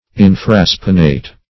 Search Result for " infraspinate" : The Collaborative International Dictionary of English v.0.48: Infraspinate \In`fra*spi"nate\, Infraspinous \In`fra*spi*nous\, a. [Infra + spinate, spinous.]